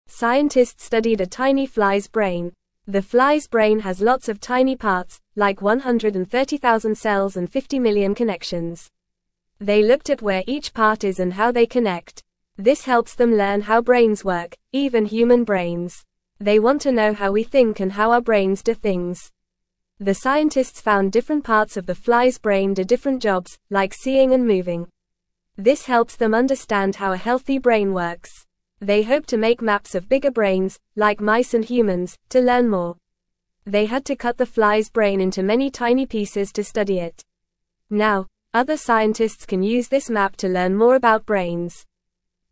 Fast
English-Newsroom-Beginner-FAST-Reading-Scientists-study-tiny-fly-brain-to-learn-more.mp3